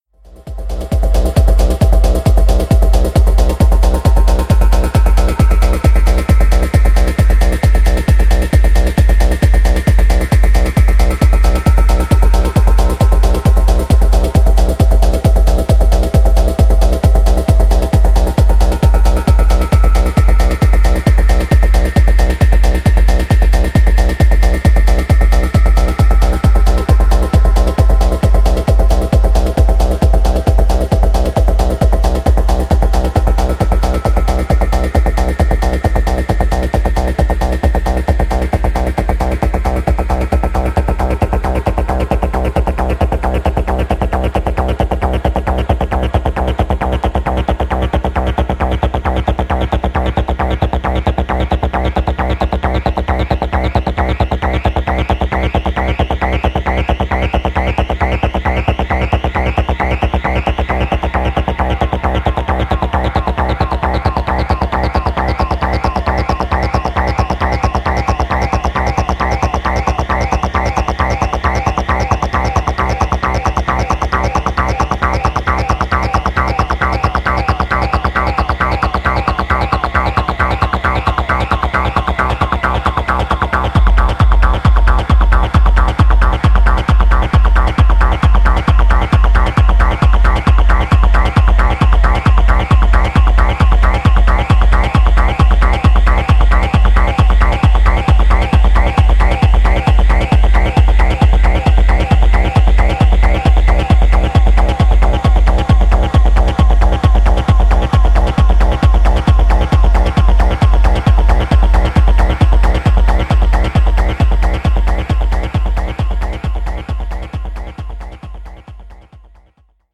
ミニマル・トランスなアシッドベースが強力な推進力を発揮する